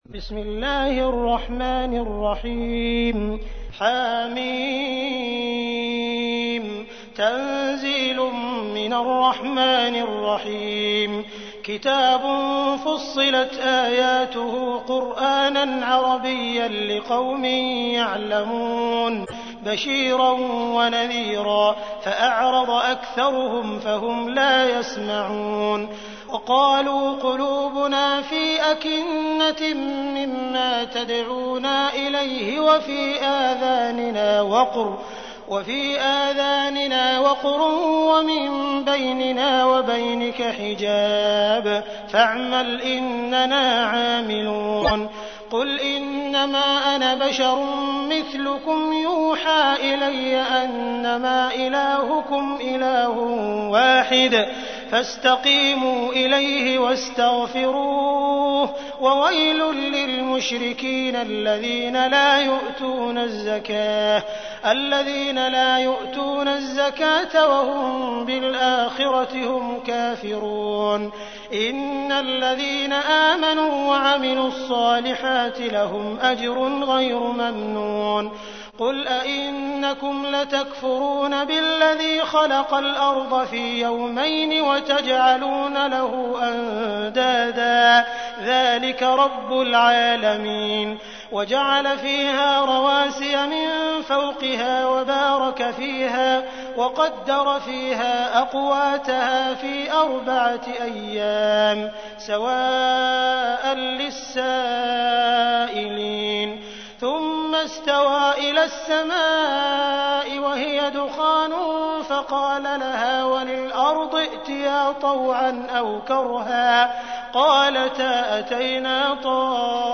تحميل : 41. سورة فصلت / القارئ عبد الرحمن السديس / القرآن الكريم / موقع يا حسين